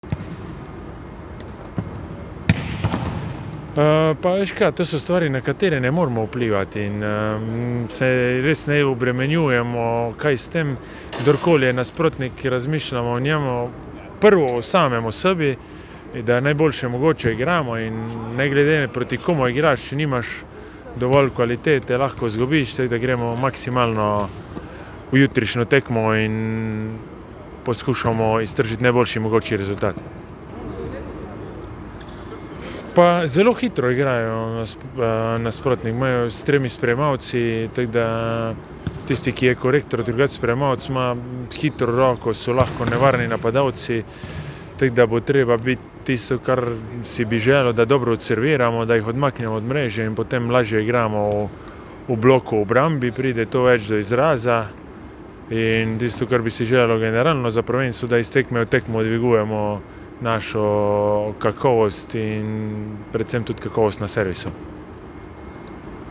Izjava Tine Urnaut:
izjava Urnaut .mp3